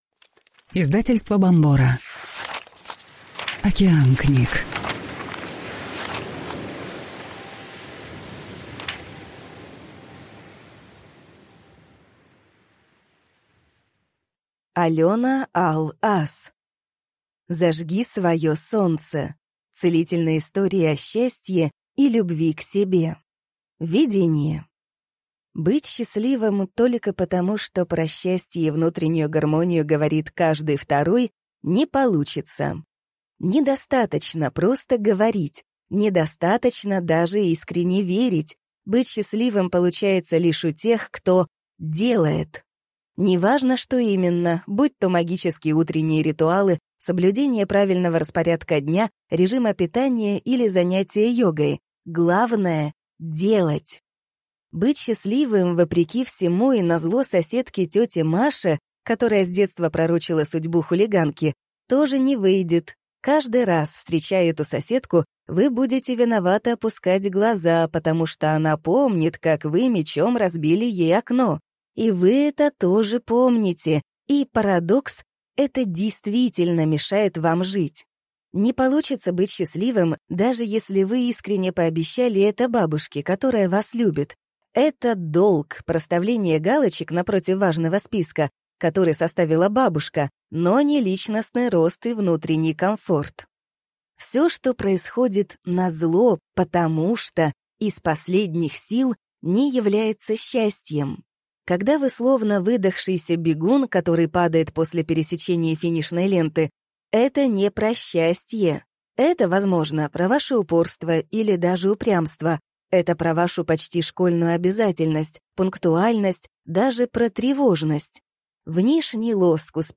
Аудиокнига Зажги свое солнце. Целительные истории о счастье и любви к себе | Библиотека аудиокниг